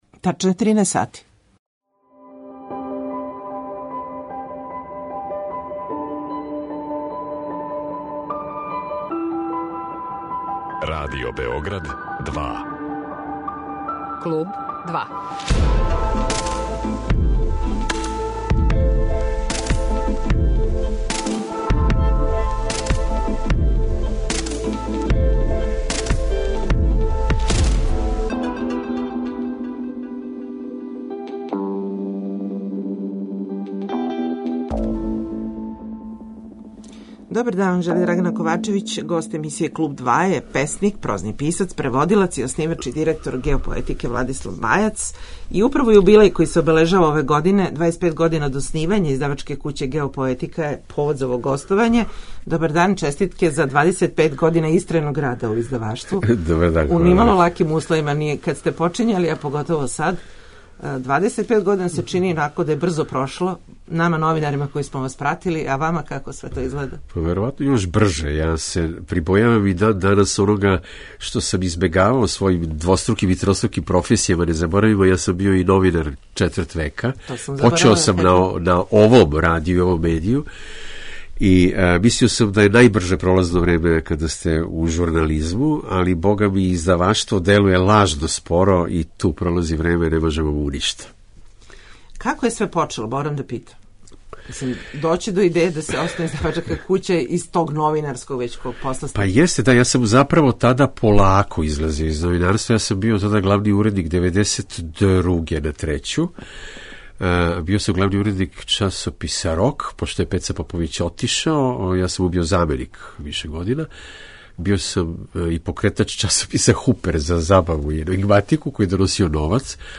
Гост емисије 'Клуб 2' је Владислав Бајац писац и издавач.